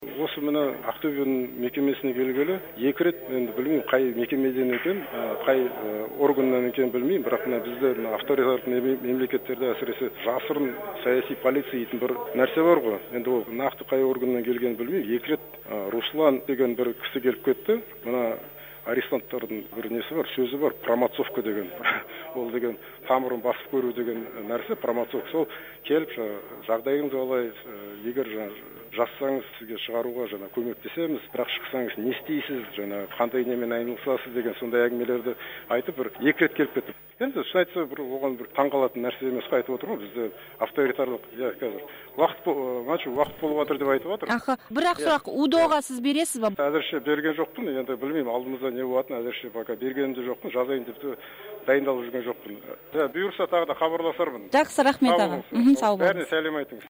Осы тұста Макс Боқаев "мына жақта уақыт болды дейді" деп, телефонмен сөйлесуге берілген он минуттың аяқталғанын меңгезді.